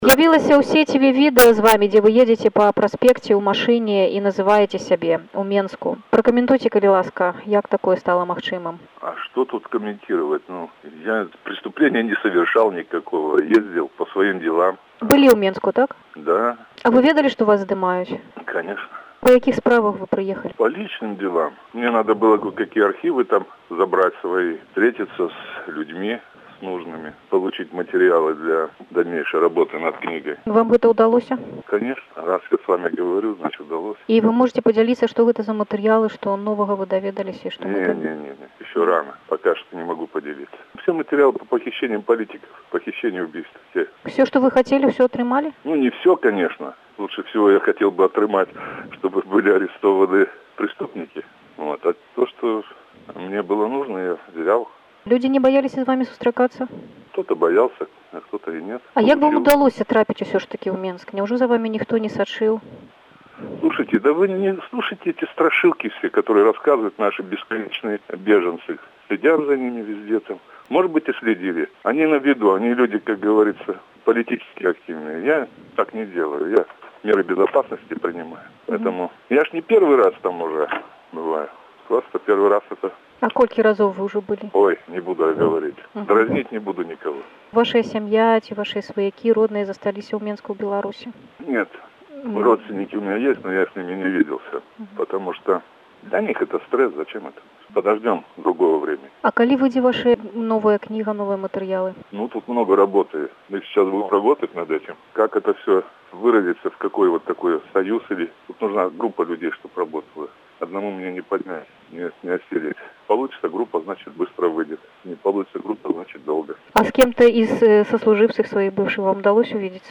Embed share Інтэрвію Алега Алкаева by Радыё Свабода || Радио Свобода Embed share The code has been copied to your clipboard.